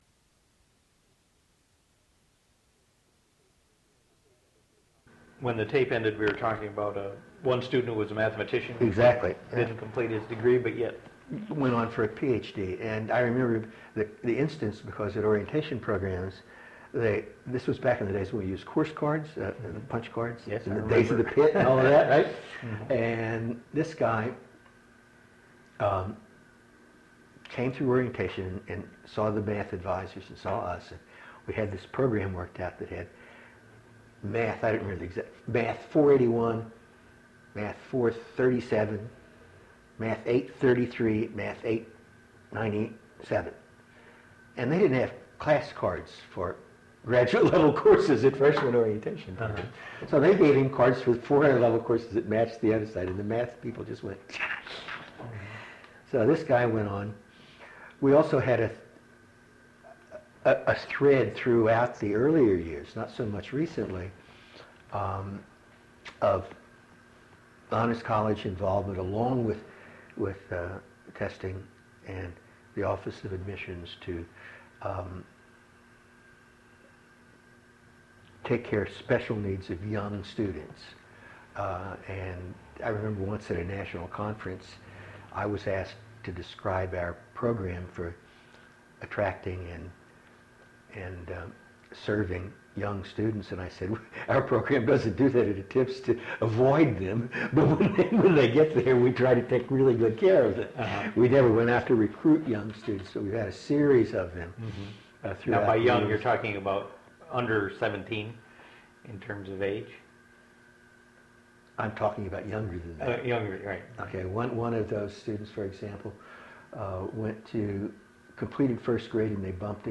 Original Format: Audiocassettes